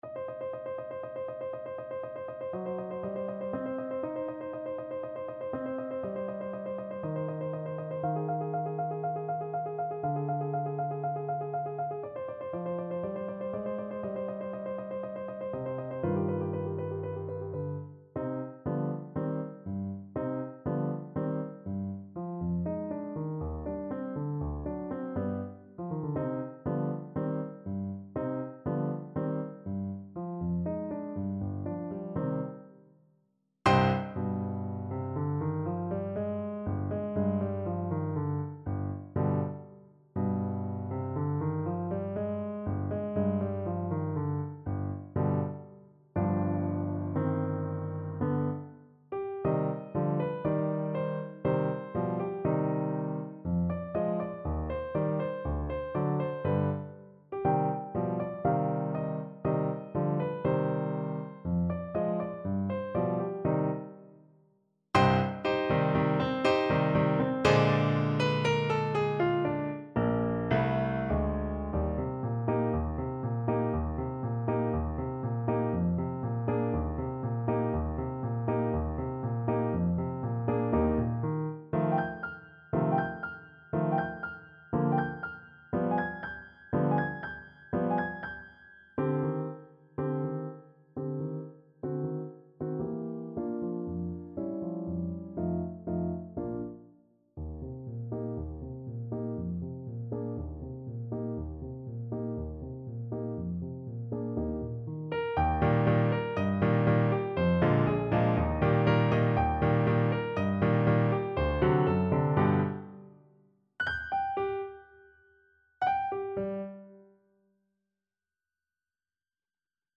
Play (or use space bar on your keyboard) Pause Music Playalong - Piano Accompaniment Playalong Band Accompaniment not yet available transpose reset tempo print settings full screen
C minor (Sounding Pitch) D minor (Clarinet in Bb) (View more C minor Music for Clarinet )
= 120 Allegro molto vivace (View more music marked Allegro)
Classical (View more Classical Clarinet Music)